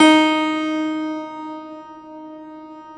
53e-pno11-D2.wav